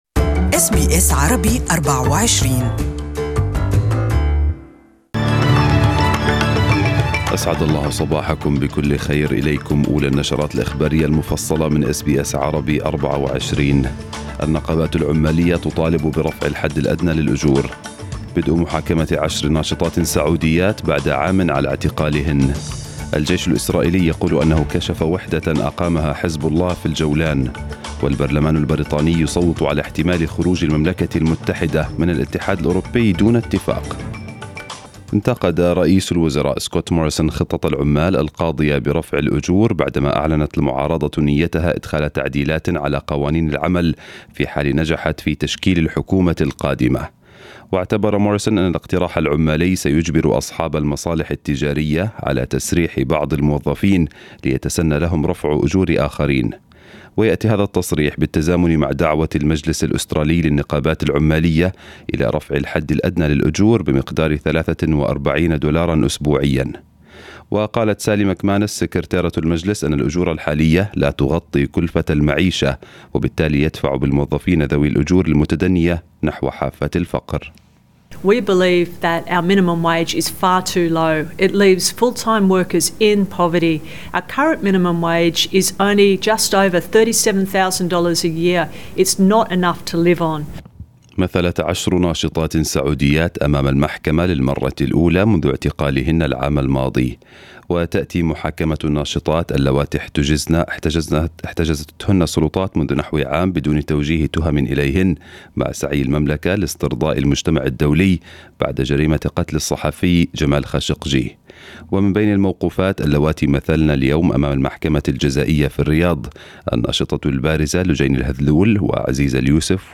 News Bulletin in Arabic for this morning